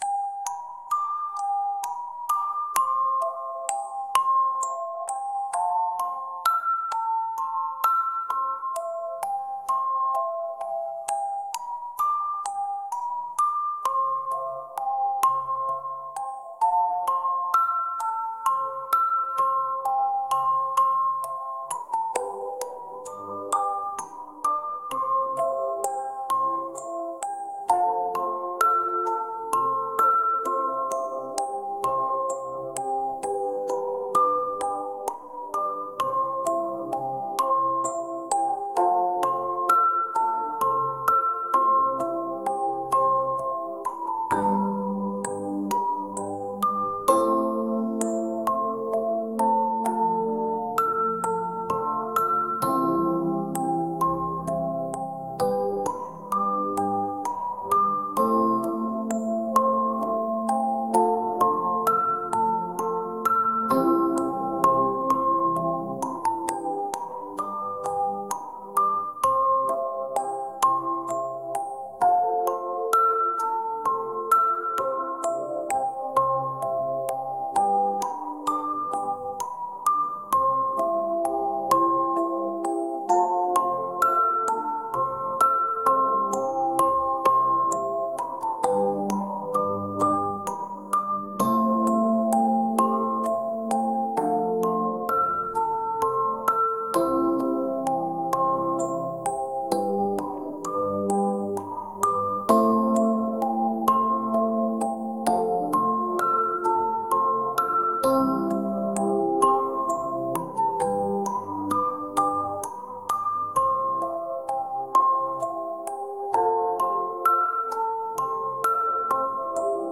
オルゴール